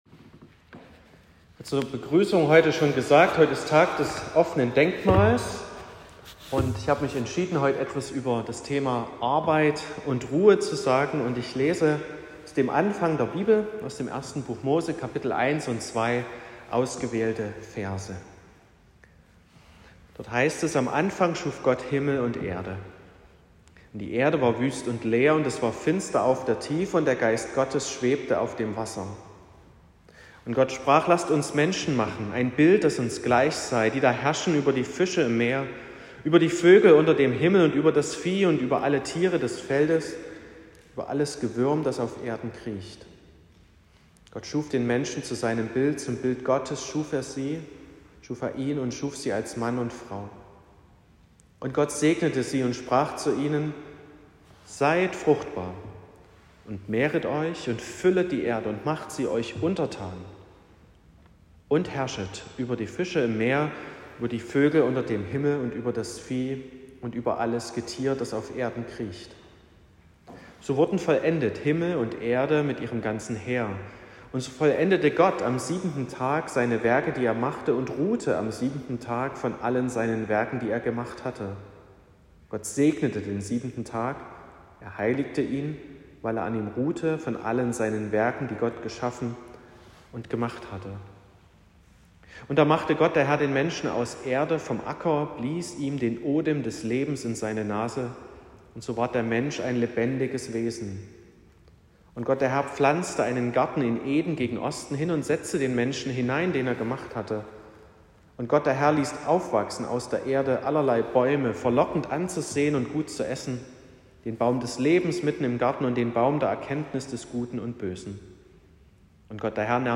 14.09.2025 – Gottesdienst
Predigt und Aufzeichnungen